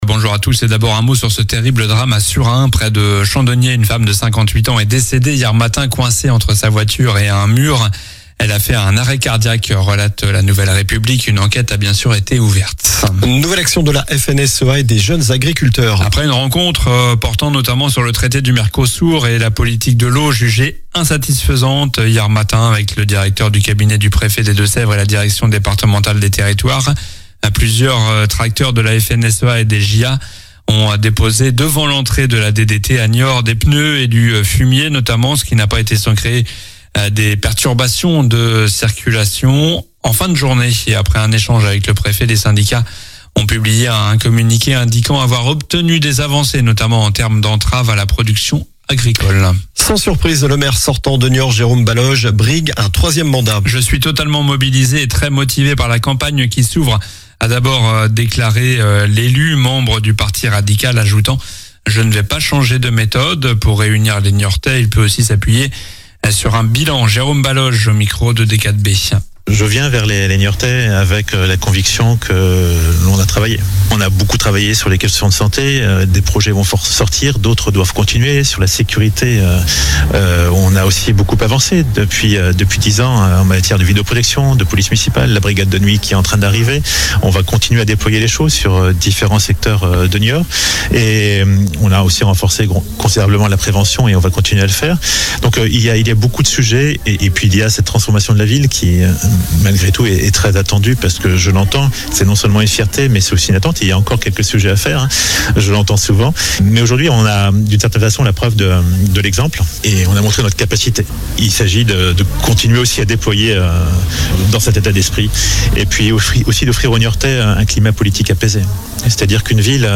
Journal du samedi 17 janvier (matin)